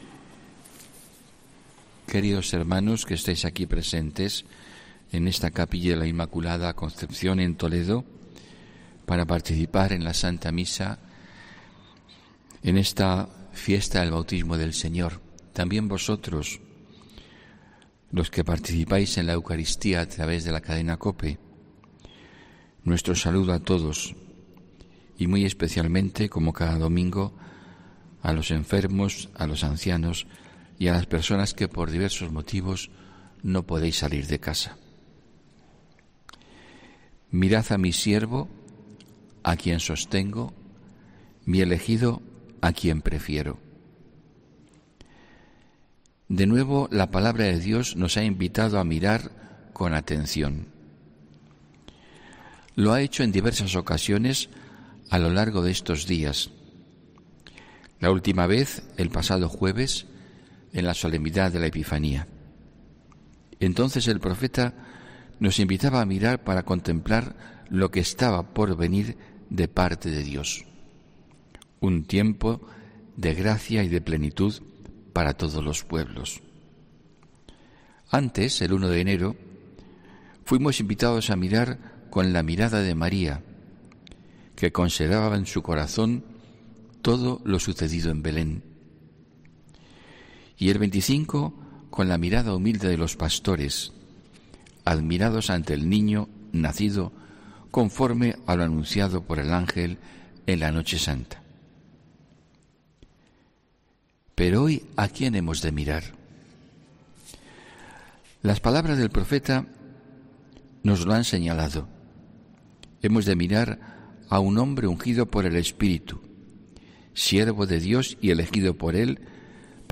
HOMILÍA 9 ENERO 2022